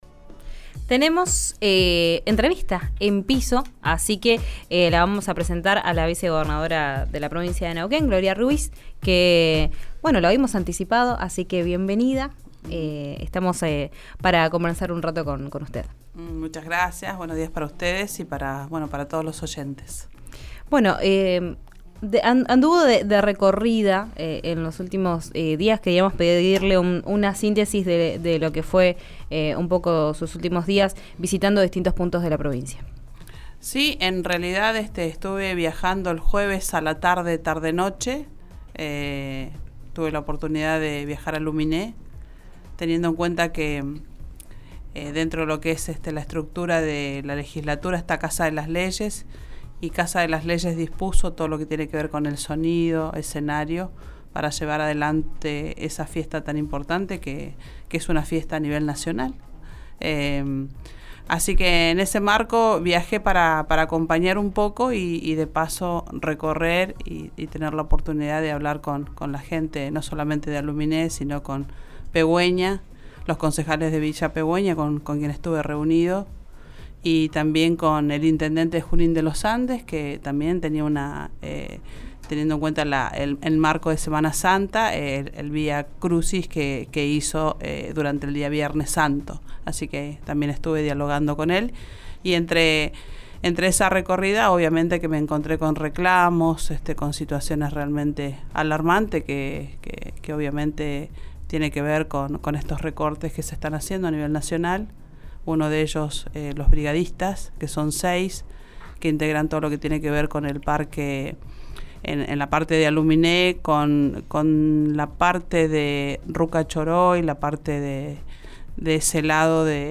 Escuchá a la vicegobernadora de Neuquén, Gloria Ruiz, en RÍO NEGRO RADIO:
En una entrevista con RÍO NEGRO RADIO, la funcionaria también adelantó los próximos proyectos que ingresarán a la Legislatura.